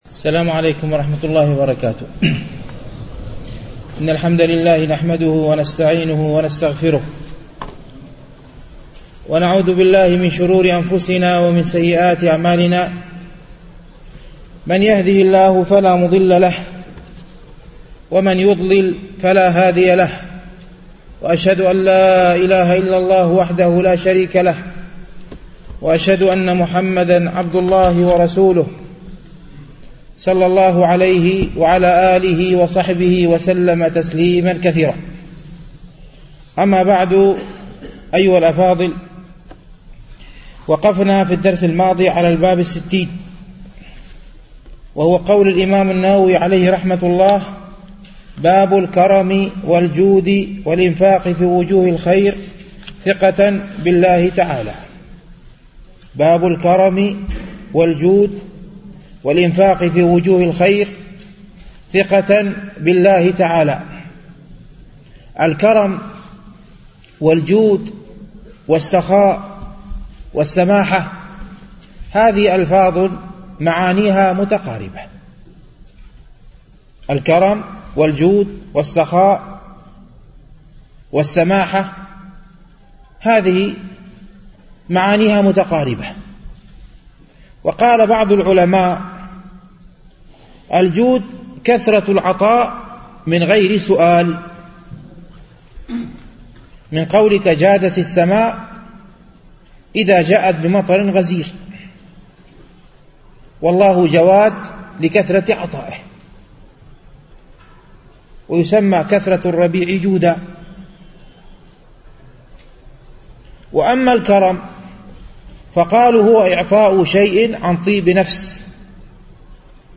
شرح رياض الصالحين - الدرس الحادي الخمسون بعد المئة